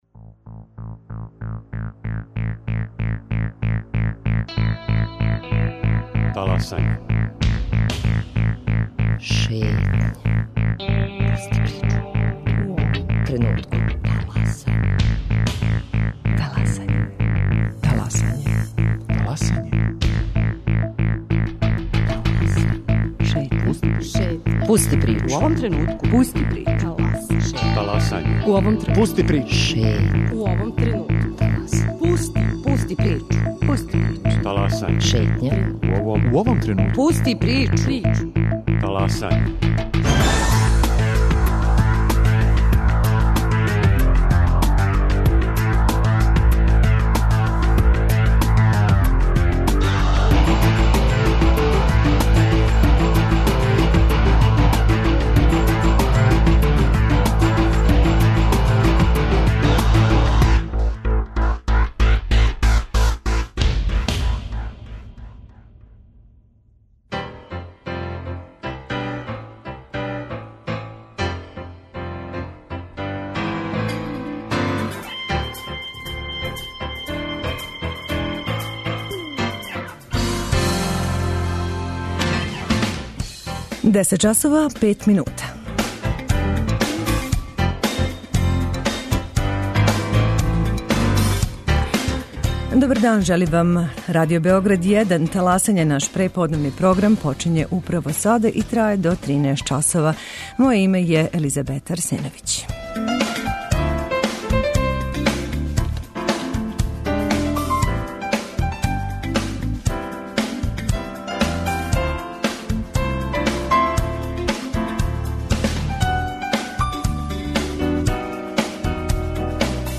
19. новембар је Дан Српске академије наука и уметности, која ове године обележава 171. годину постојања, што је чини најстаријом научном установом код нас. Тим поводом председник САНУ, академик Никола Хајдин дао је изјаву за наш програм.